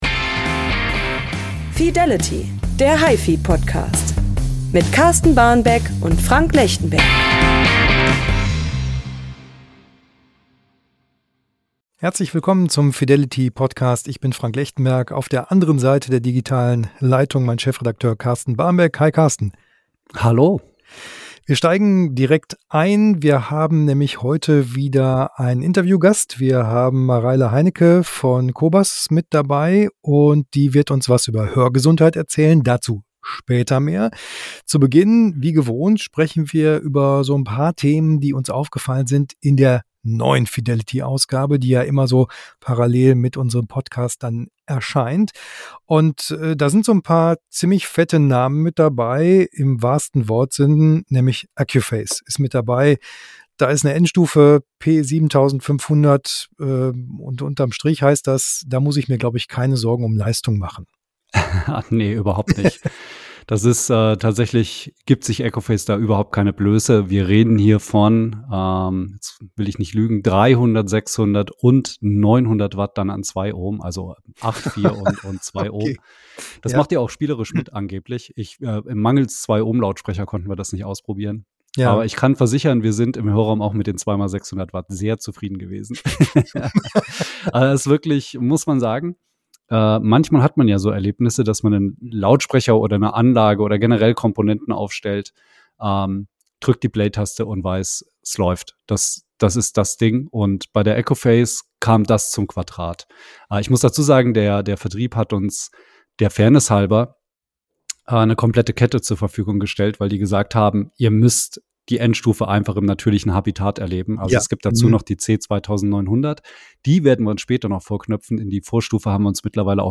Geräte, Gerüchte und gute Musik! Der gepflegte Talk am Kaffeetisch zu unserem liebsten Hobby.